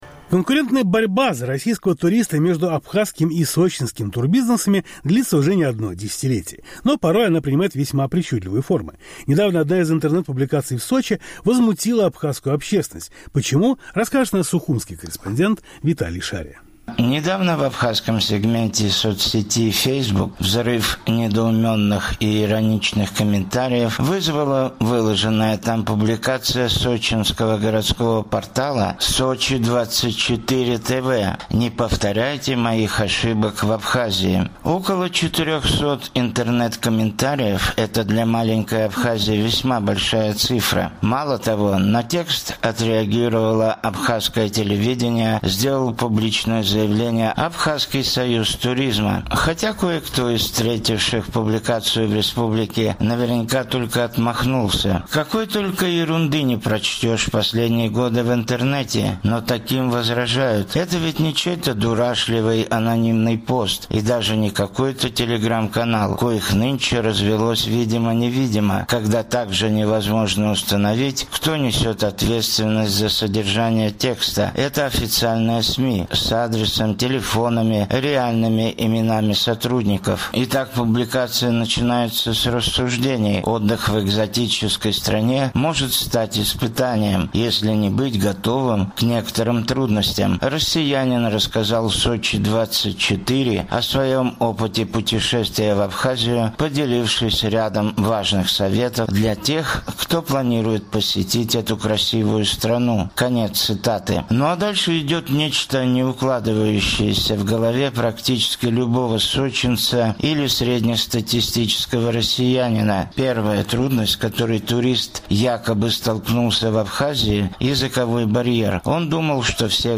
(Смех).